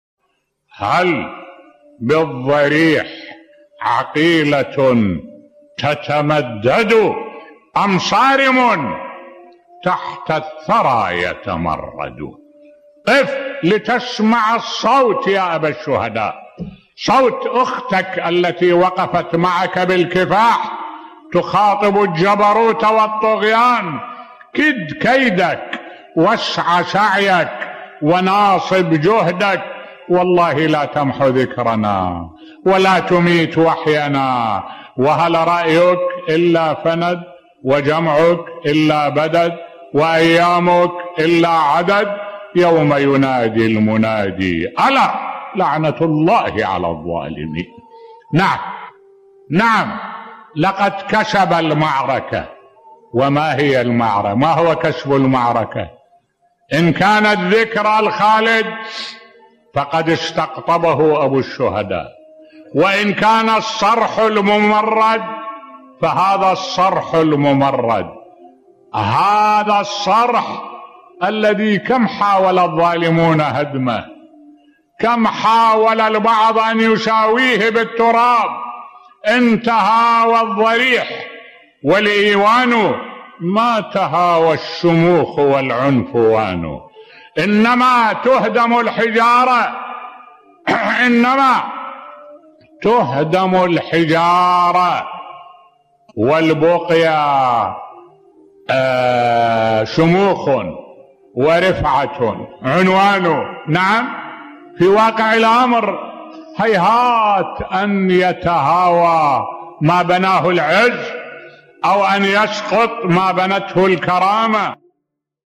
ملف صوتی السيدة زينب تواجه الطغيان الأموي بصوت الشيخ الدكتور أحمد الوائلي